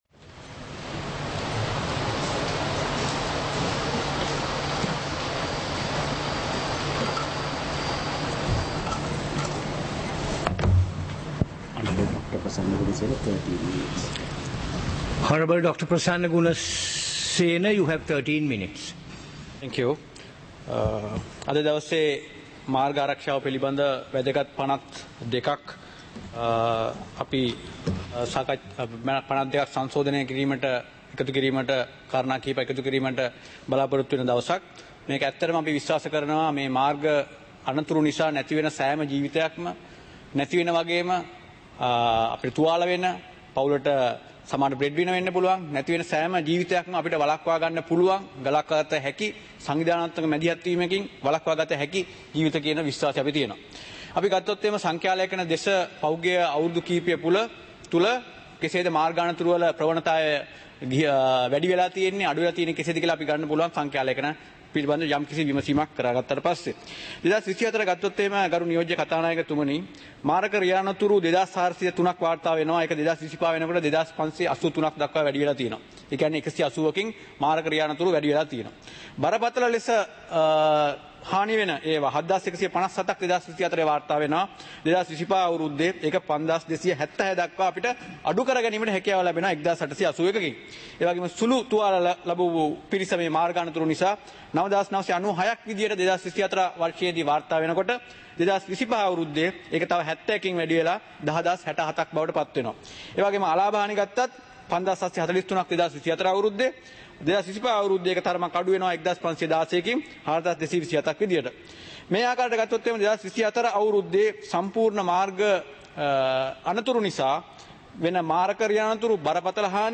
சபை நடவடிக்கைமுறை (2026-01-08)
பாராளுமன்ற நடப்பு - பதிவுருத்தப்பட்ட